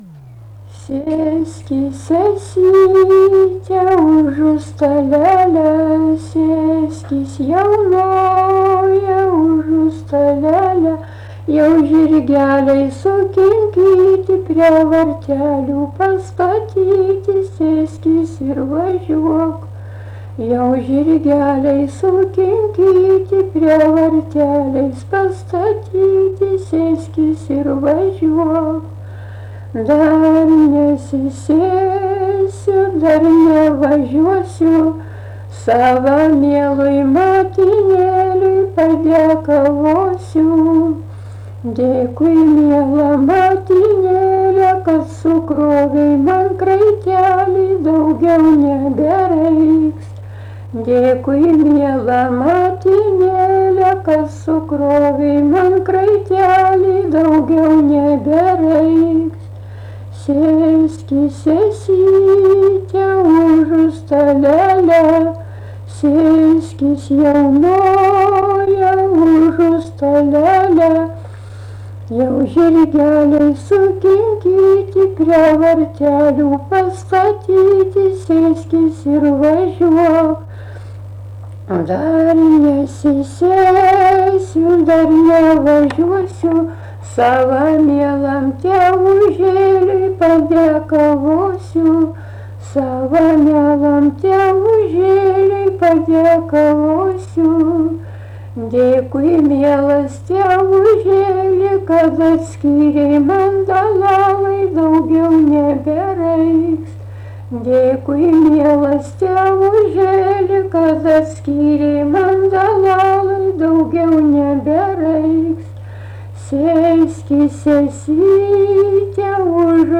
Erdvinė aprėptis Jūžintai
Atlikimo pubūdis vokalinis